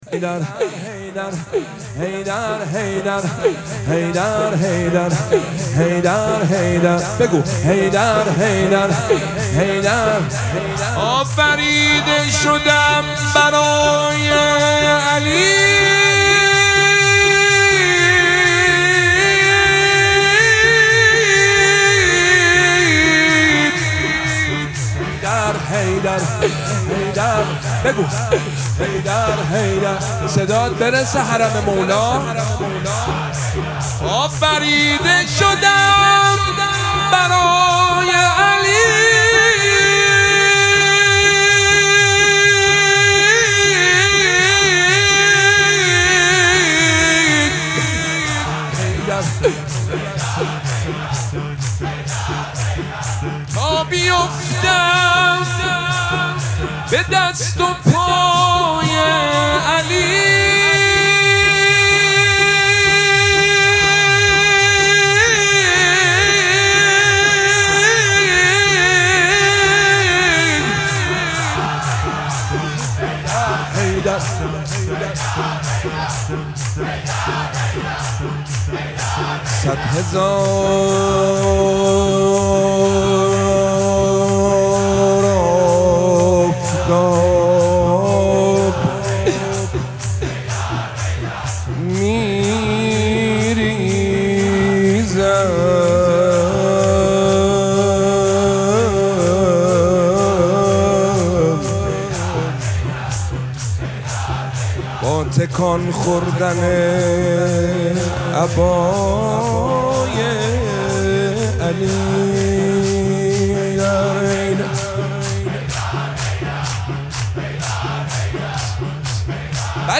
مراسم مناجات شب ششم ماه مبارک رمضان
نغمه خوانی